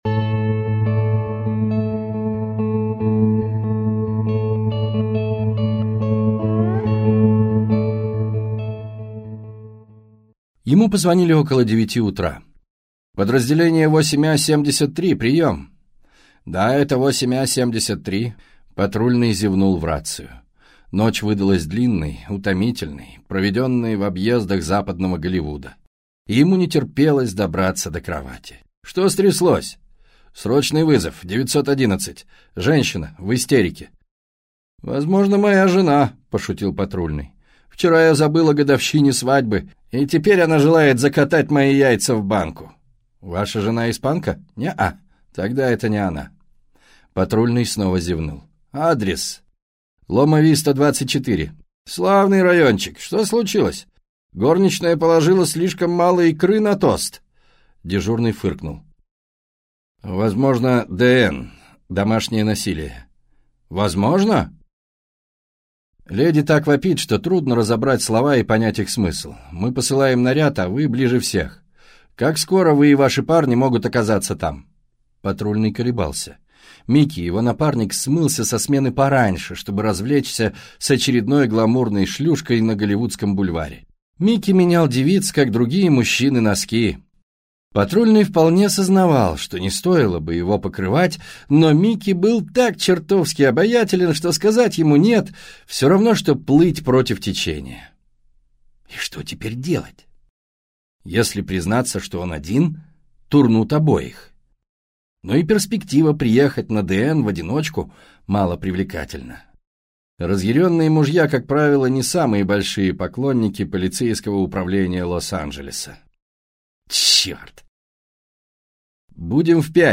Аудиокнига Сидни Шелдон. Ангел тьмы | Библиотека аудиокниг